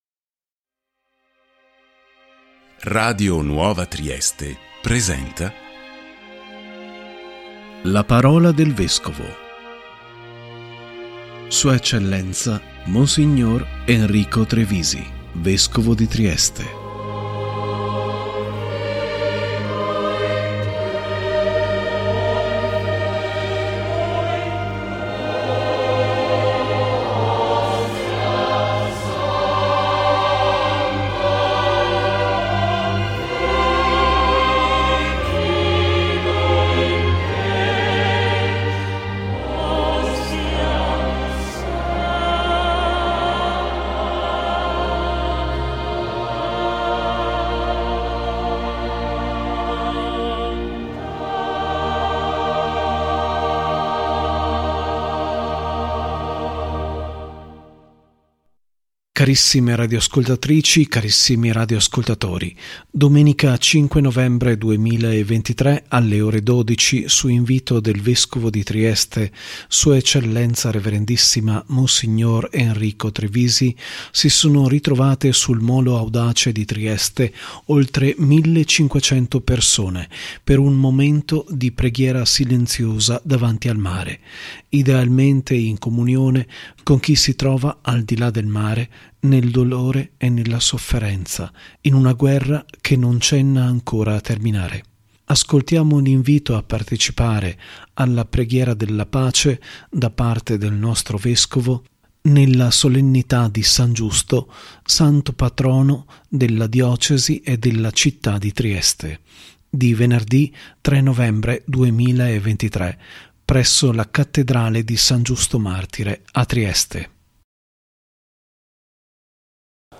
A conclusione di questo programma la parte cocnlusiva dell’omelia di S.E. Rev.ssima Mons. Enrico Trevisi, Vescovo di Trieste, nella Solennità del Santo Patrono della Diocesi di Trieste e della città di Trieste San Giusto martire di Venerdì 3 novembre 2023 presso la Cattedrale di San Giusto martire a Trieste.